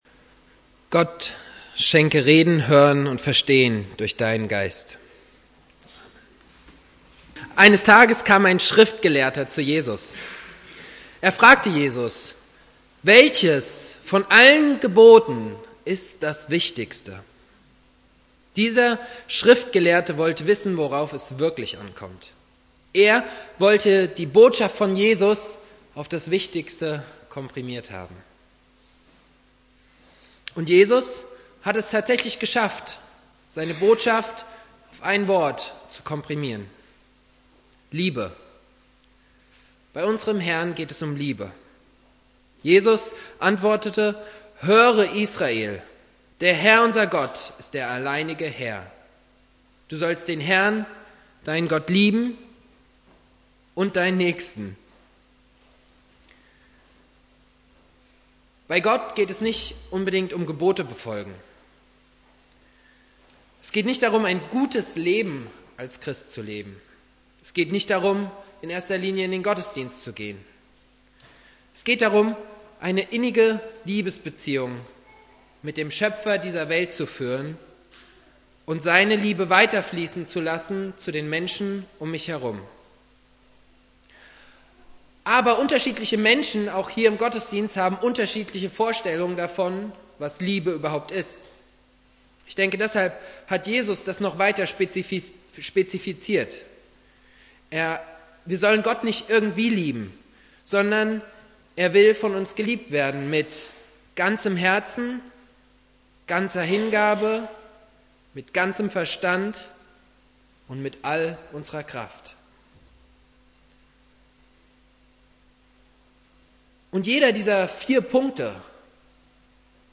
Predigtthema: Mit ganzem Verstand lieben Matthäus 5, 28 Ich aber sage euch: Jeder, der eine Frau mit begehrlichem Blick ansieht, hat damit in seinem Herzen schon Ehebruch mit ihr begangen.